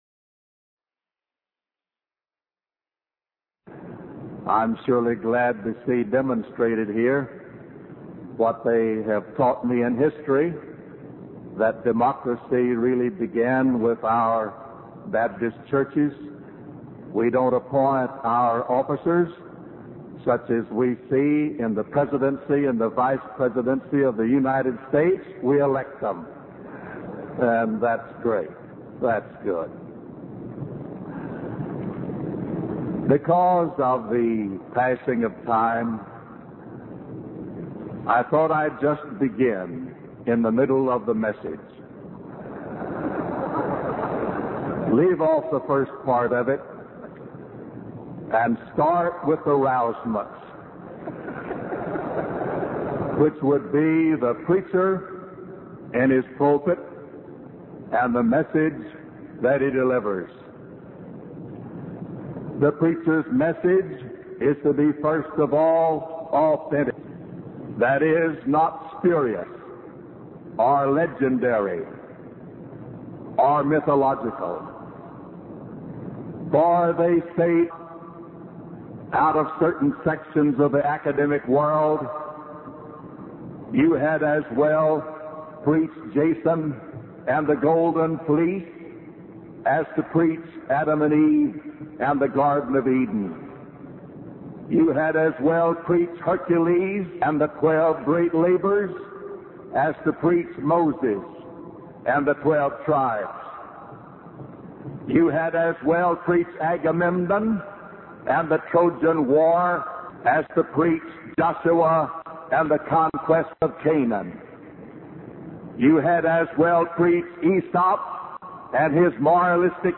1975 Address to the Southern Baptist Convention by W.A. Criswell | SermonIndex
In this sermon, the preacher emphasizes the importance of delivering an authentic and apologetic message from the pulpit.